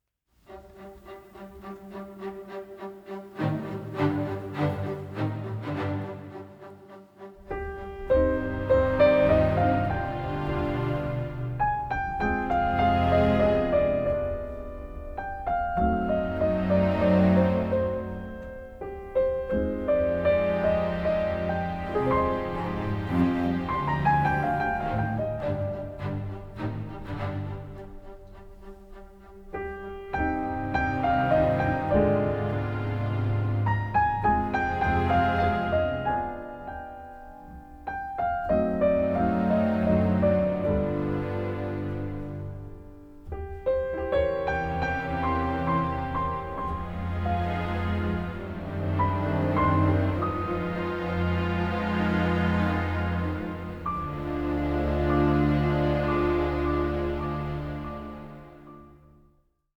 intense, emotional and moving large-orchestral scores
The music was recorded at Air Studios, London